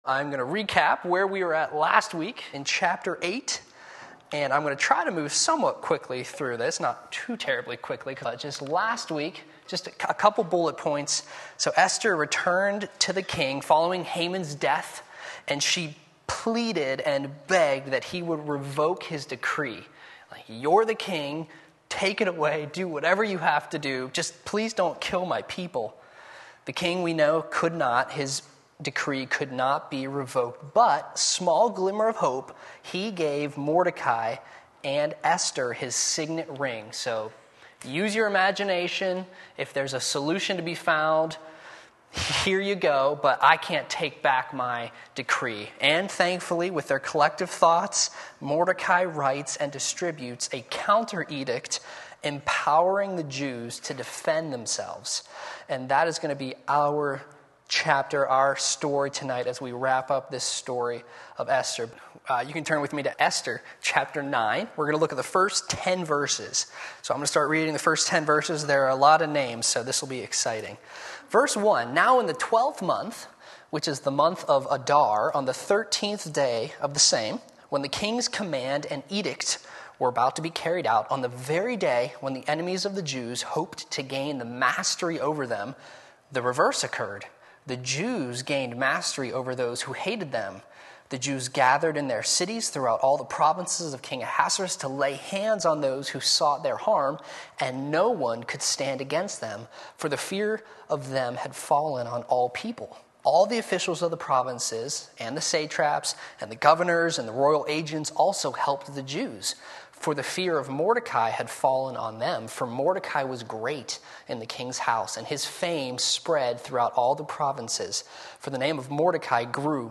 10:3 Wednesday Evening Service